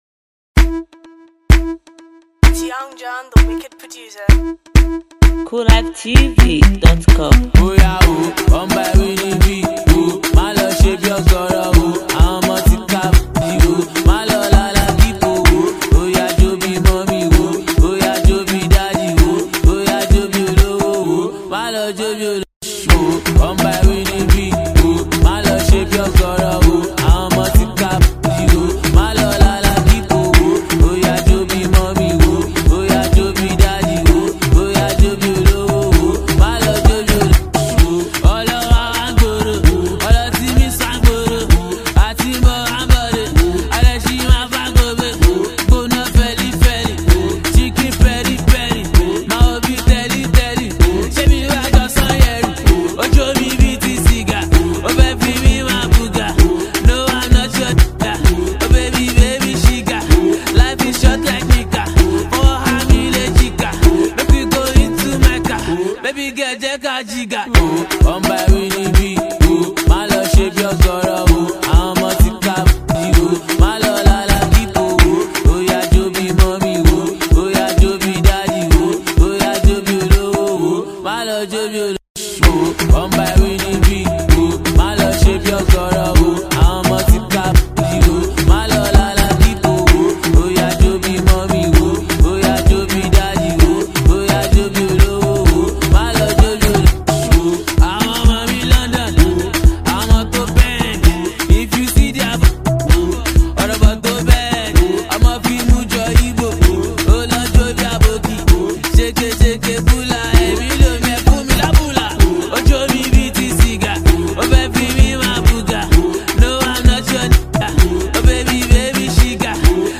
This is undoubtedly a street banger